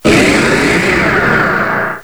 direct_sound_samples / cries